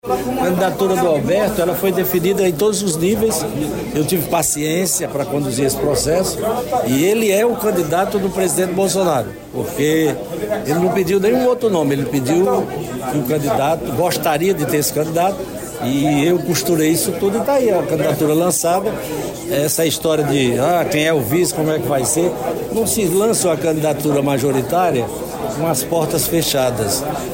A fala ocorreu após o ato de lançamento da pré-candidatura de Alberto Neto a Prefeitura de Manaus, na sede do PL, nesta sexta-feira, 22.